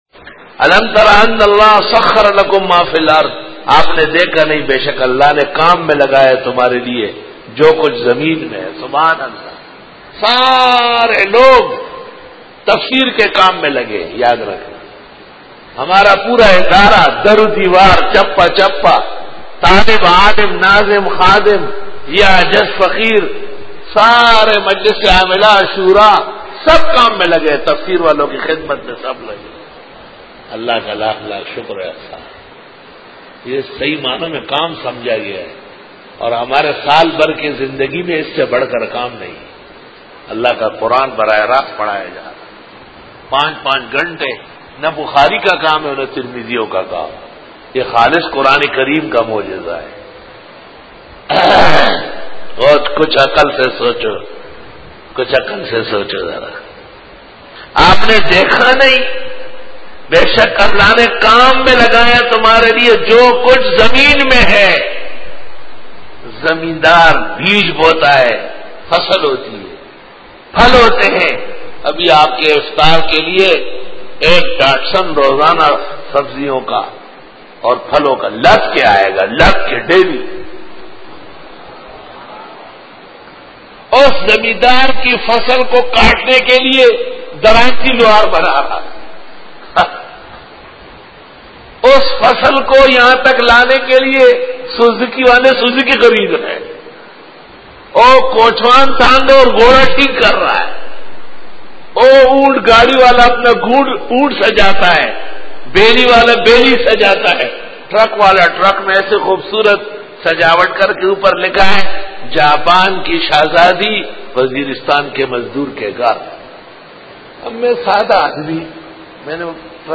سورۃ الحج رکوع-09 Bayan